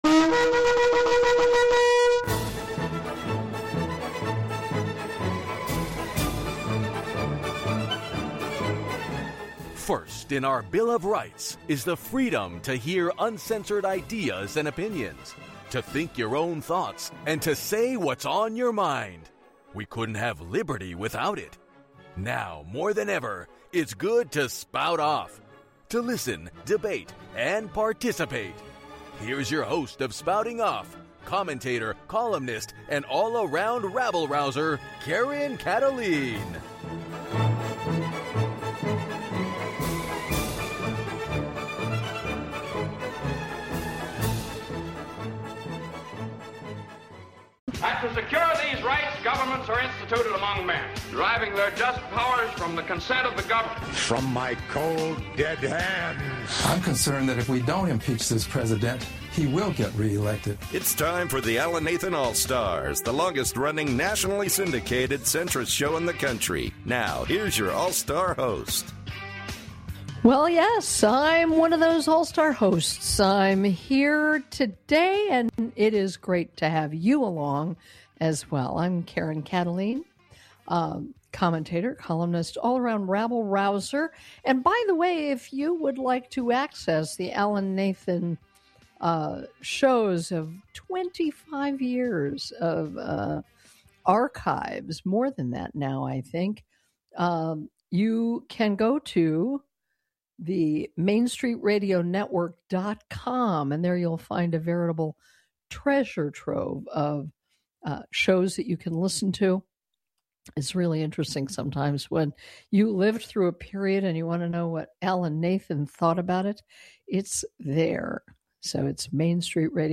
Talk Show Episode, Audio Podcast, Spouting Off and Institutional Corruption and the Epstein Files: A Deep Dive into Systemic Cover-ups on , show guests , about Institutional Corruption,the Epstein Files,A Deep Dive into Systemic Cover-ups,Uncensored Ideas,Systemic Fraud,Blackmail State,Epstein Document Status,Systemic Fraud in Healthcare,Home Care,Washington Corruption, categorized as Entertainment,News,Politics & Government,Local,National,World,Society and Culture